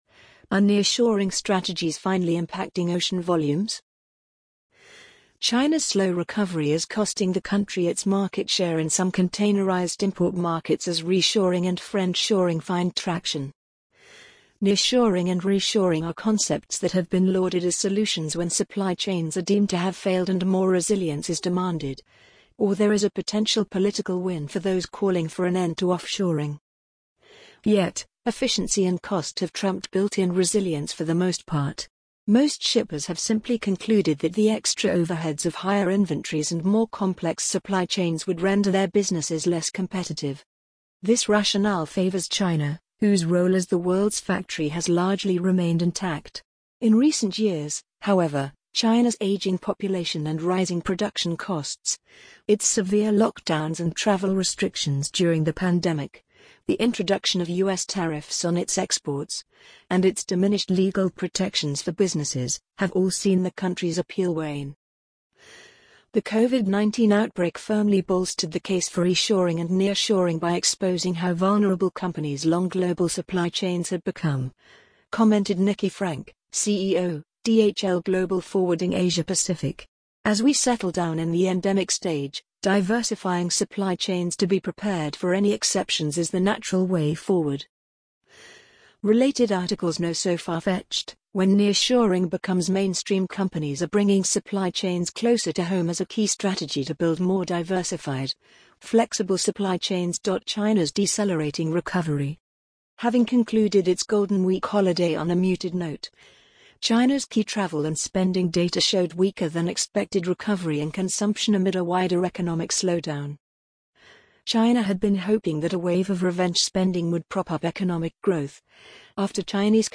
amazon_polly_46890.mp3